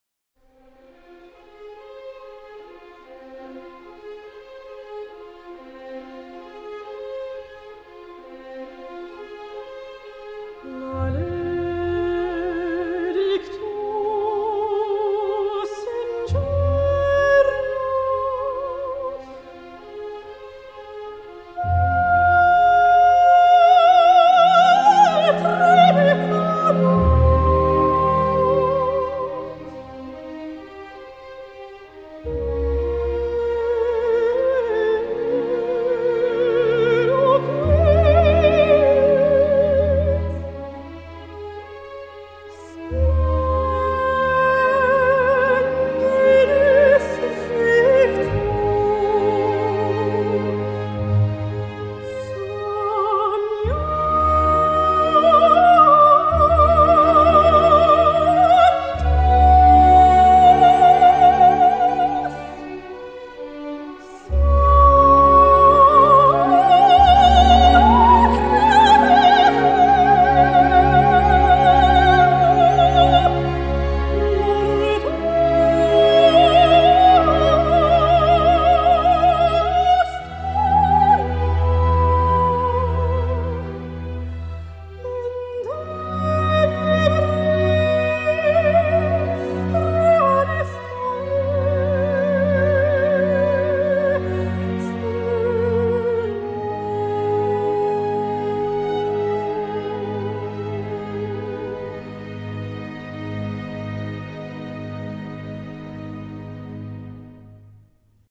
Classique dans la forme, ludique sur le fond.